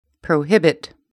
/proʊˈhɪbɪt/